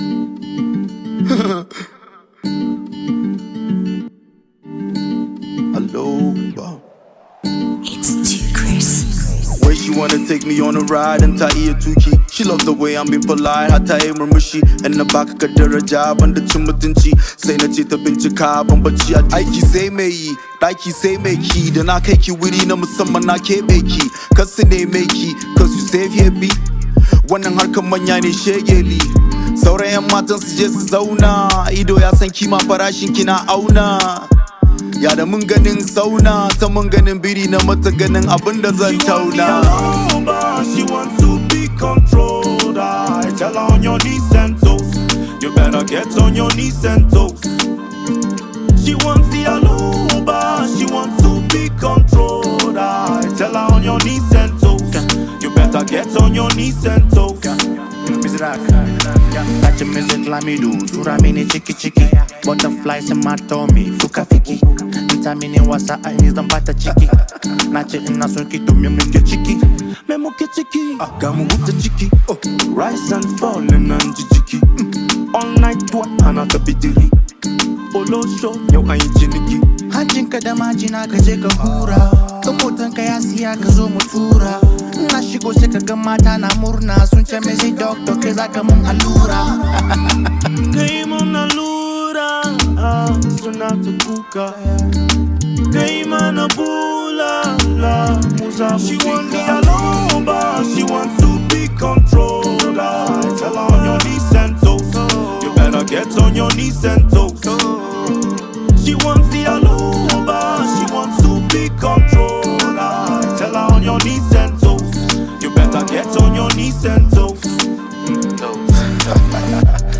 hausa music track